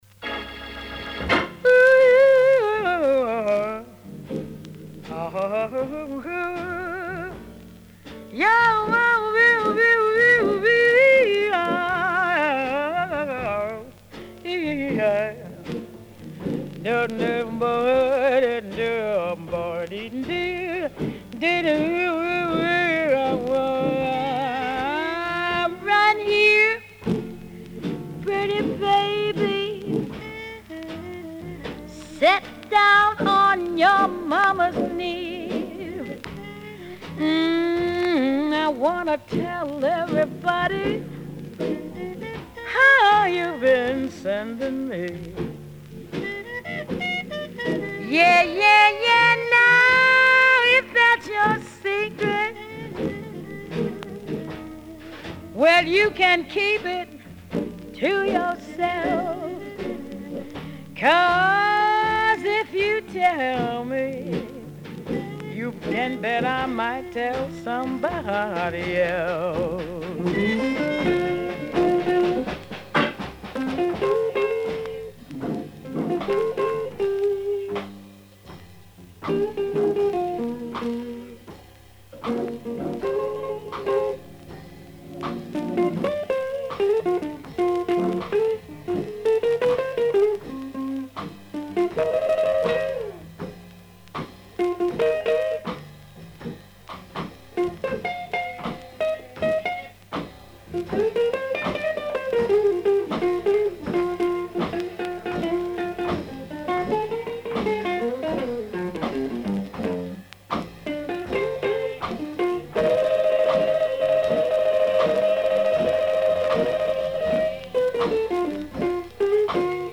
Жанр - Jazz, Стиль: Cool Jazz, Ragtime, Dixieland, Swing.